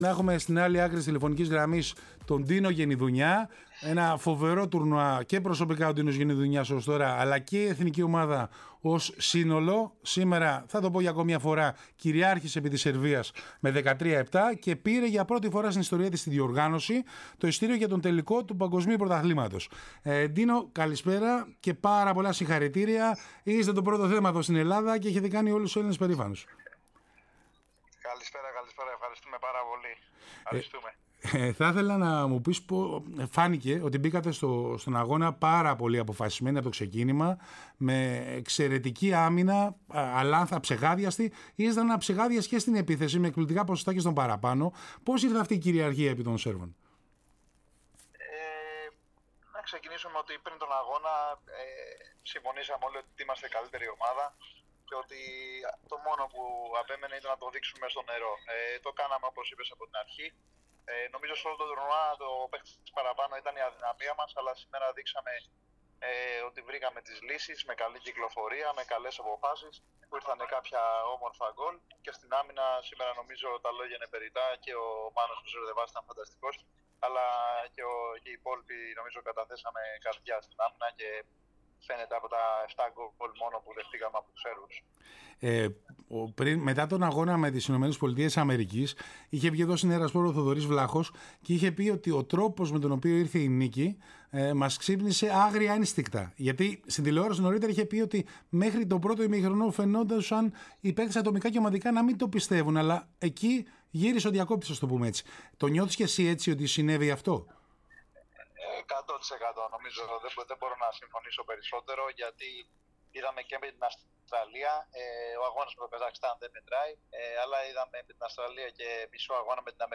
Ο Έλληνας πρωταθλητής μίλησε αμέσως μετά τη νίκη-θρίαμβο της Εθνικής επί της Σερβίας με 13-7 στον ημιτελικό του Παγκοσμίου Πρωταθλήματος υγρού στίβου και πανηγύρισε την πρόκριση στον τελικό της διοργάνωσης.